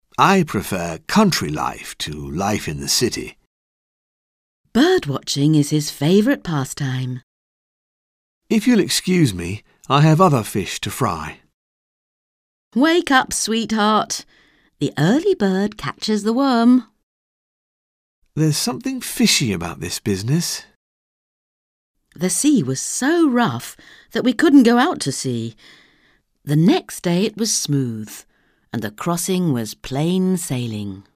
Un peu de conversation - La campagne, la mer